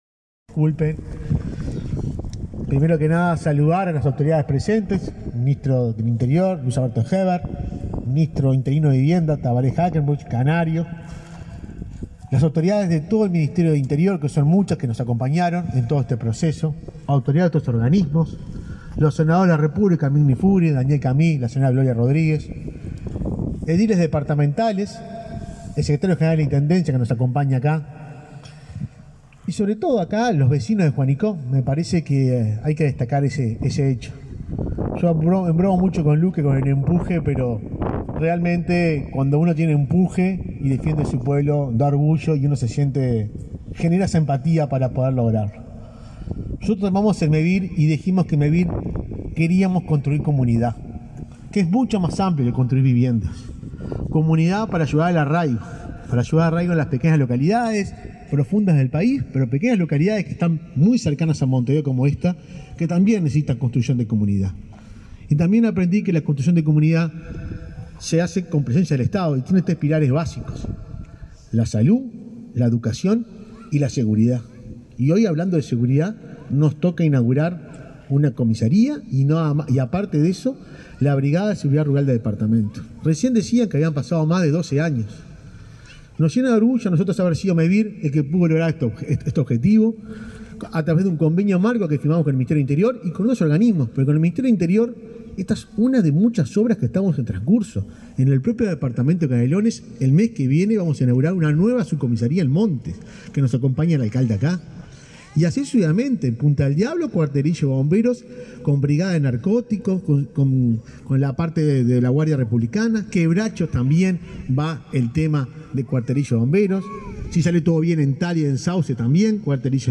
Conferencia de prensa por inauguración de subcomisaría en Juanicó, Canelones
Participaron en el evento el ministro Luis Alberto Heber; el presidente de Mevir, Juan Pablo Delgado, y el subsecretario de Vivienda, Tabaré Hackenbruch.